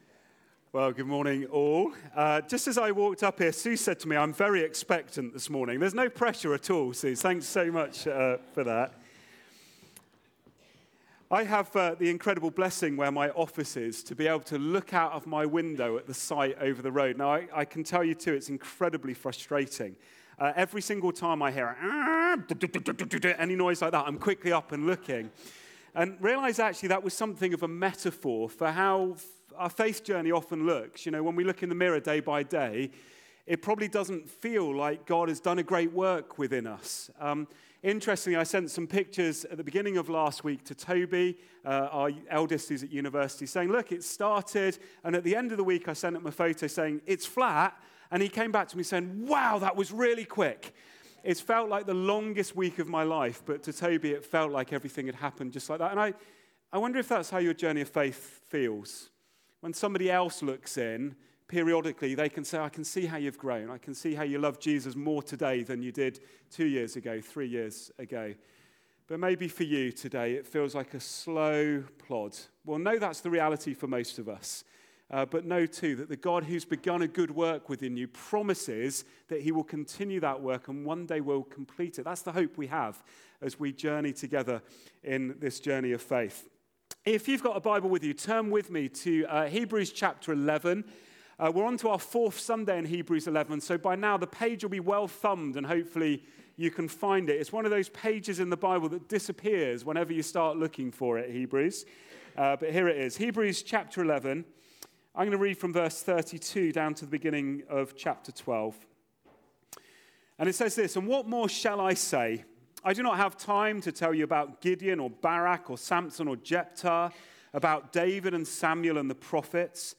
Message 04, Embracing Humility, Philippians 2:12-18, Luke 2:25-40